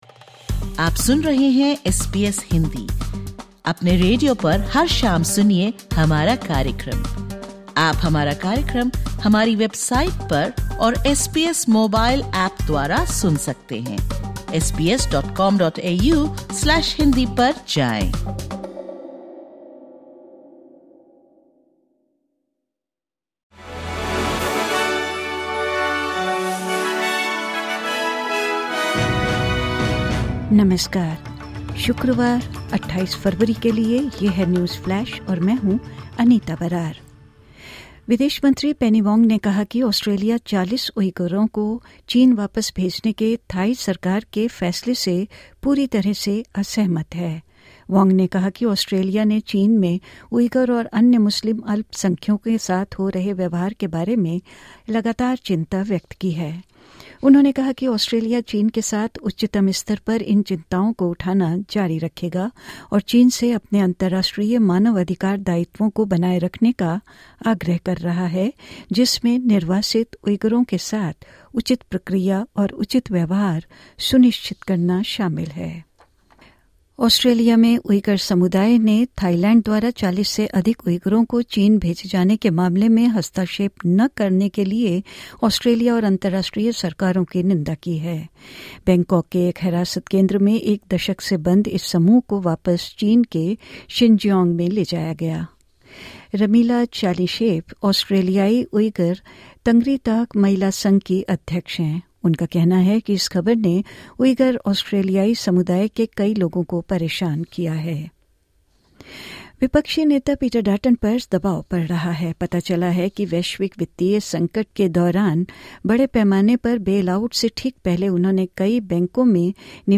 सुनें ऑस्ट्रेलिया और भारत से 28/02/2025 की प्रमुख खबरें।